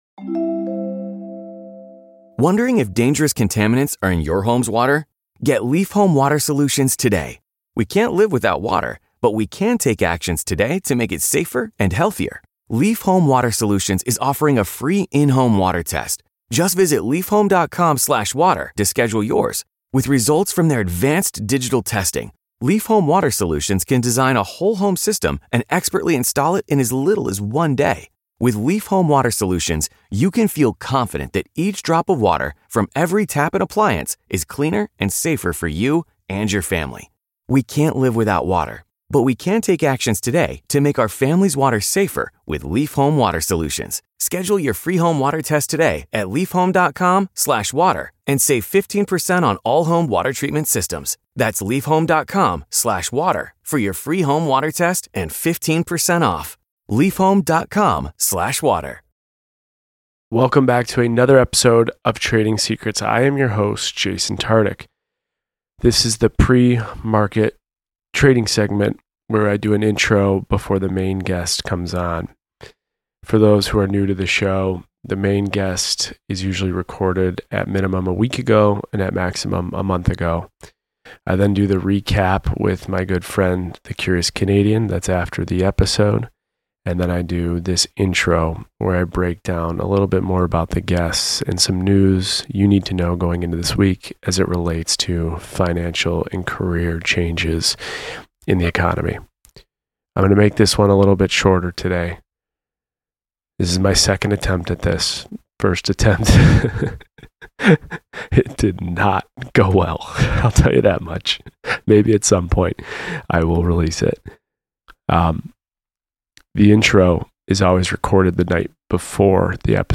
Host: Jason Tartick Co-Host
Guests: Blake Horstmann & Giannina Gibelli